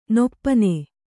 ♪ noppane